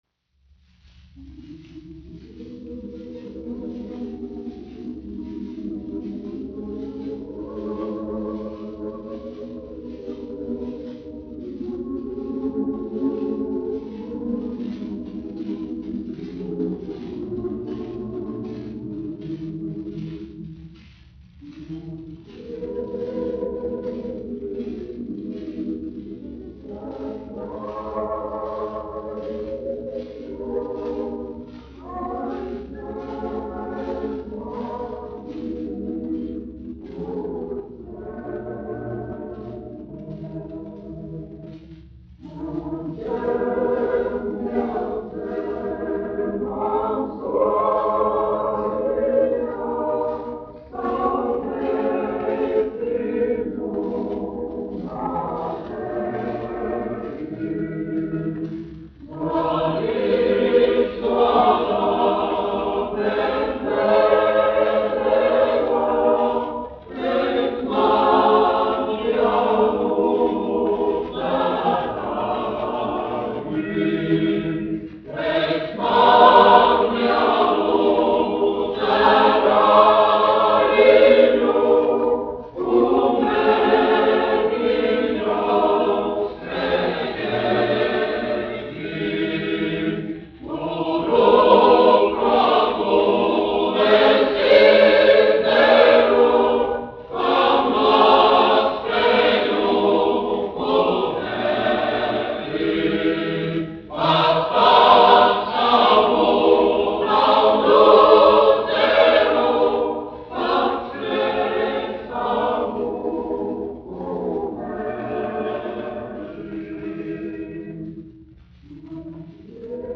Daugava (koris), izpildītājs
1 skpl. : analogs, 78 apgr/min, mono ; 25 cm
Kori (jauktie)
Latviešu tautasdziesmas
Skaņuplate
Latvijas vēsturiskie šellaka skaņuplašu ieraksti (Kolekcija)